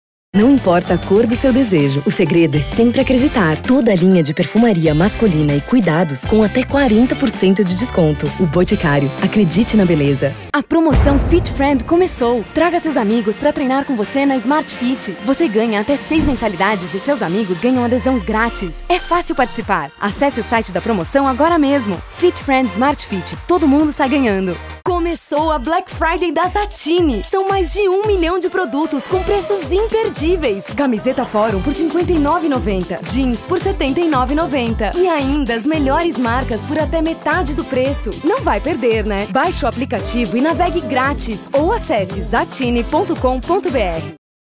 Feminino
Locução Promocional
Voz Varejo 00:44